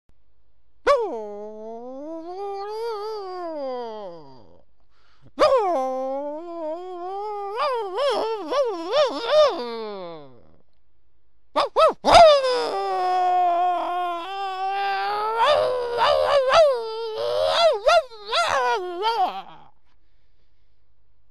De hond die zich achter de deur bevond begon, op het moment dat ik aanbelde, dergelijke kreten van geconcentreerde energie uit te slaan dat ik ervan overtuigd was dat hij karate had gestudeerd en waarschijnlijk een stevige dosis kungfu erbovenop.
meditatieklanken te horen had hij ongetwijfeld een zwarte gordel en was hij een van zijn dodelijkste kraanvogeltechnieken aan het voorbereiden om mij te vloeren.
Sound effects
karatepoedel.wma